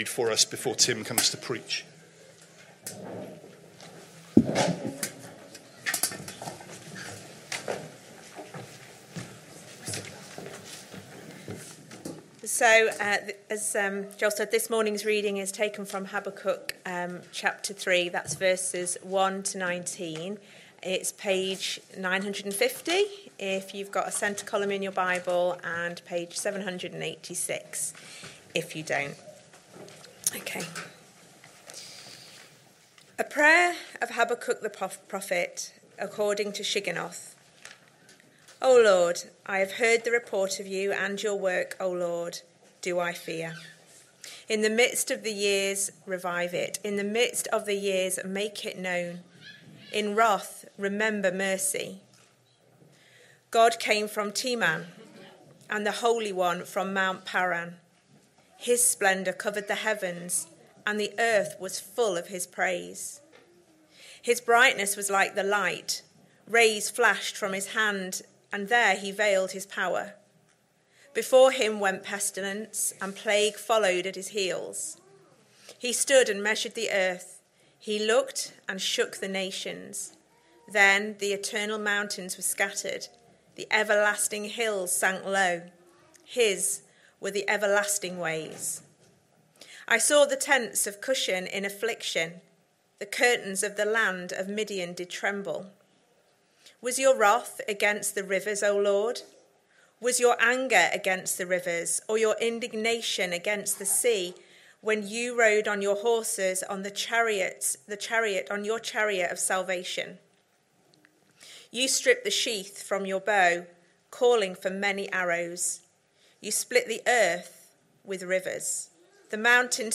Sunday AM Service Sunday 11th January 2026 Speaker